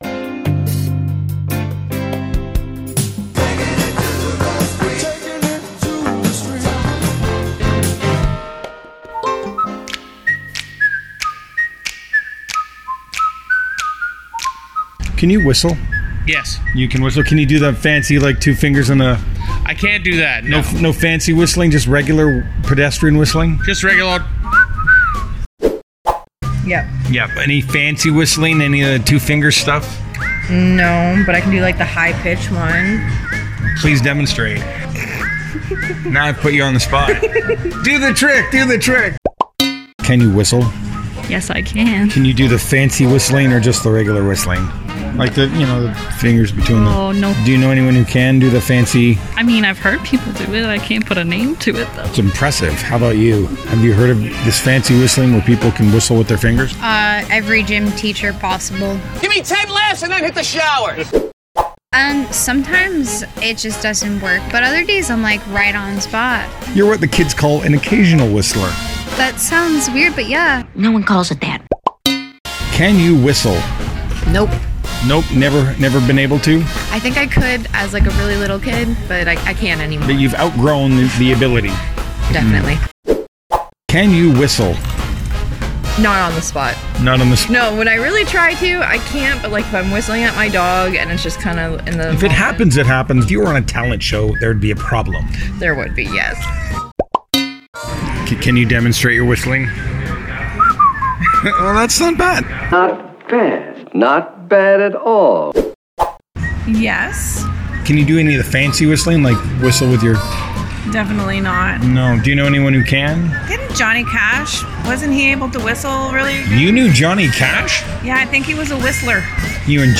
whistling-audio-1.wav